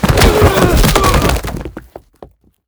Tackle4.wav